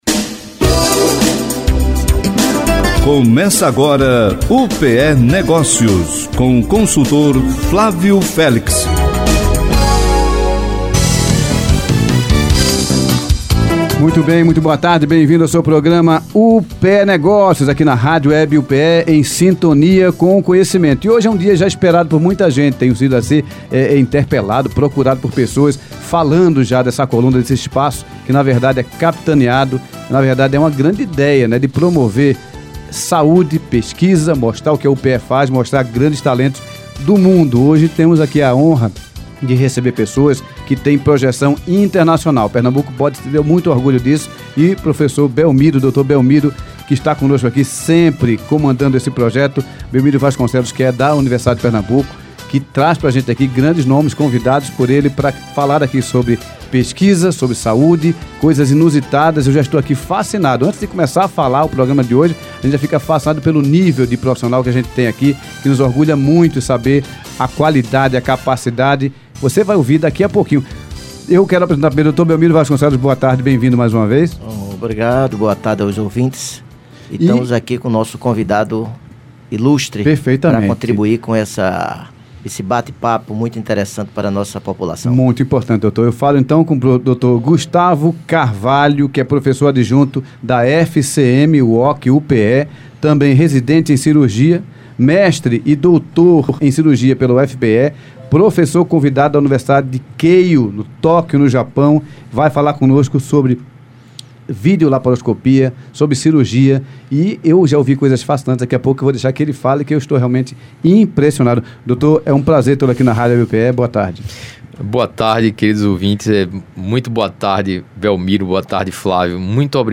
ACOMPANHE MAIS UM BATE PAPO SOBRE ESTILO DE VIDA, DESSA VEZ COM O ASSUNTO AYUERVEDA E YOGA.